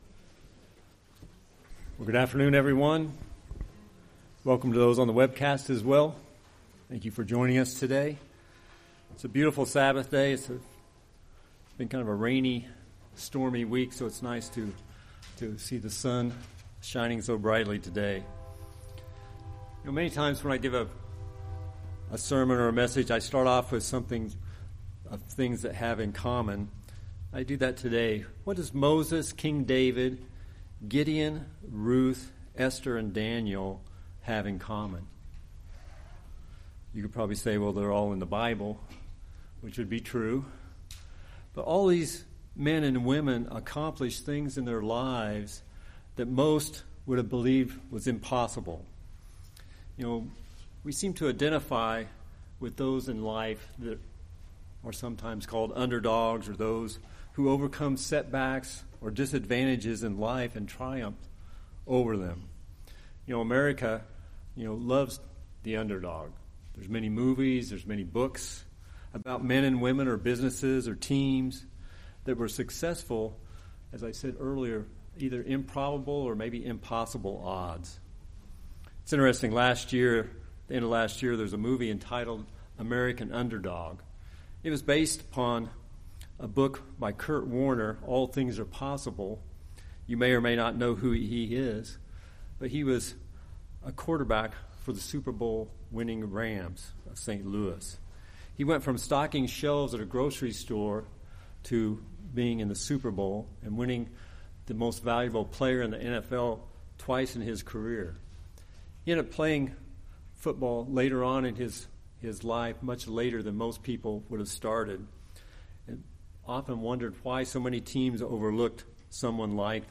We also will need to overcome problems, setbacks, and difficult times in our lives. In this sermon we will look at these biblical examples to help us see how we can accomplish what God has called us to be now and in the future.